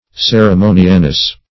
Ceremonialness \Cer`e*mo"ni*al*ness\, n. Quality of being ceremonial.
ceremonialness.mp3